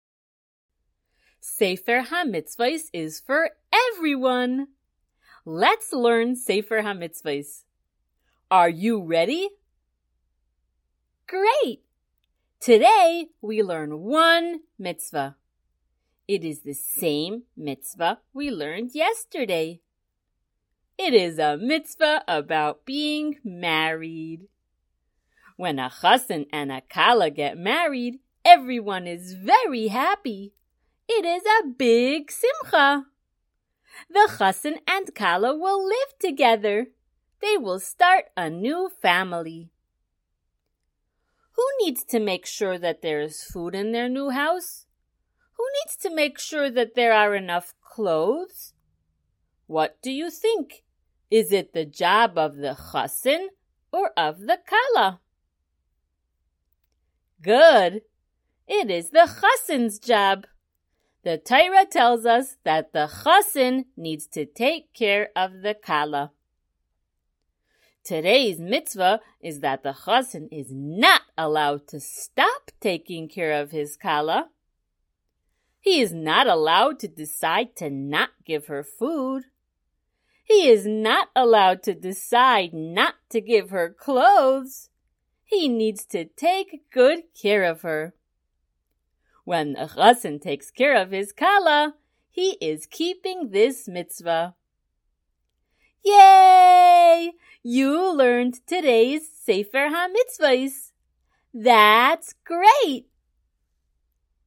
Color Shiur #75!
SmallChildren_Shiur075.mp3